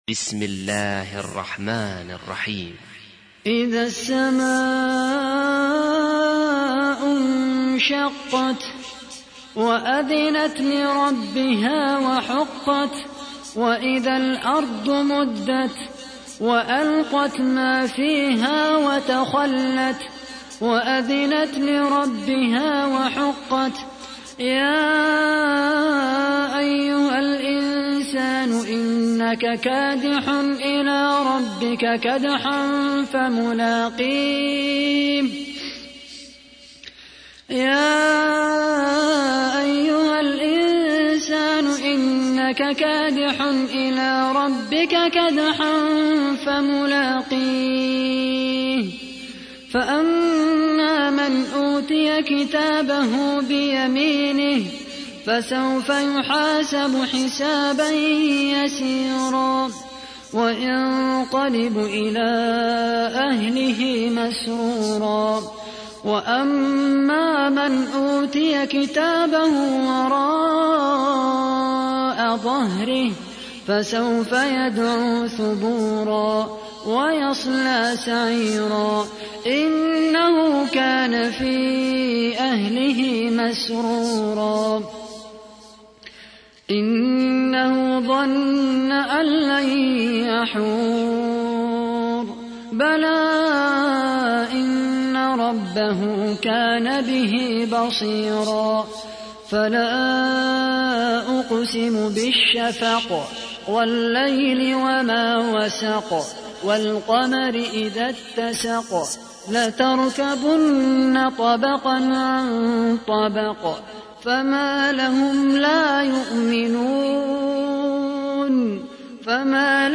تحميل : 84. سورة الانشقاق / القارئ خالد القحطاني / القرآن الكريم / موقع يا حسين